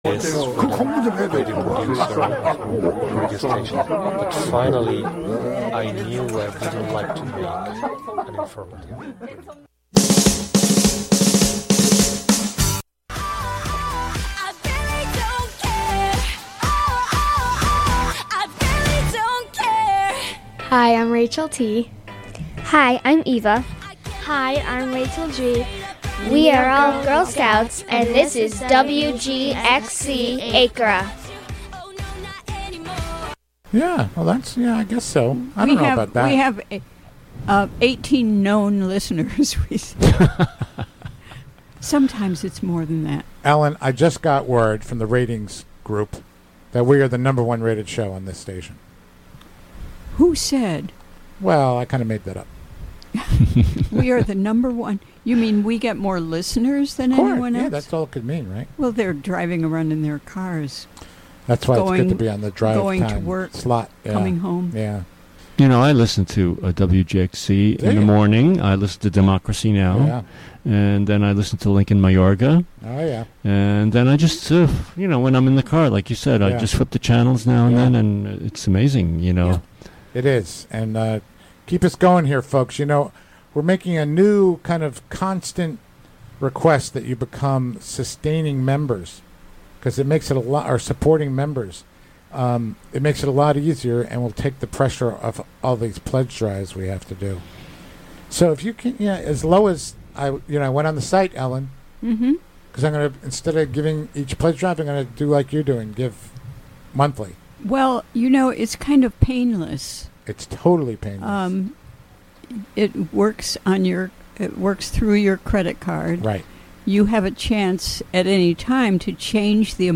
Our container: Radiolab, an open, experimental, youth-led programming and recording space. Show includes local WGXC news at beginning, and midway through. Get yr weekly dose of music appreciation, wordsmithing, and community journalism filtered through the minds and voices of the Youth Clubhouses of Columbia-Greene, broadcasting out of the Catskill Clubhouse, live on Fridays as part of All Together Now! and rebroadcast Saturday at 4 a.m. and Sunday at 10 a.m. Play In New Tab (audio/mpeg) Download (audio/mpeg)